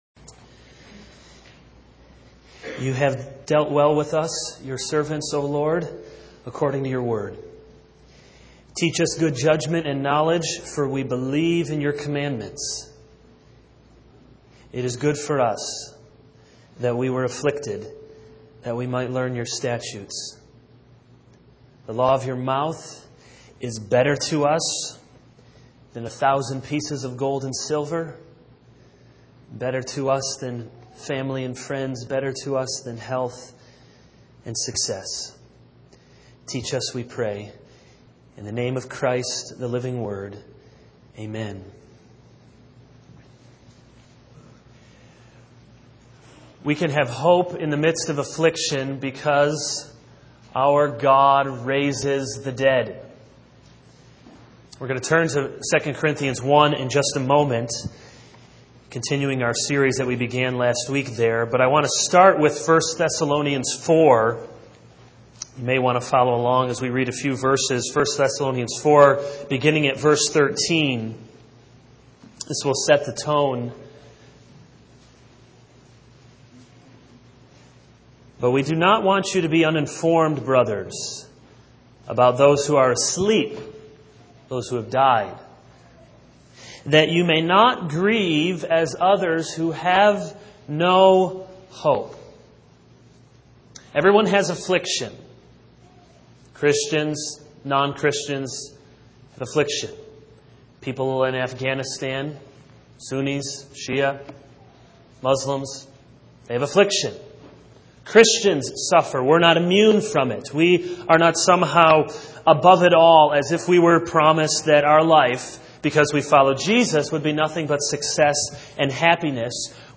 This is a sermon on 2 Corinthians 1:8-11.